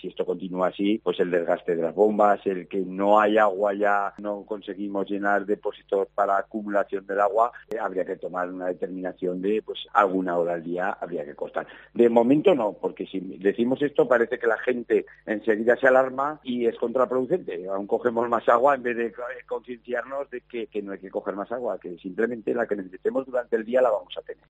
El presidente de la Mancomunidad Aguas del Huecha explica la situación de sequía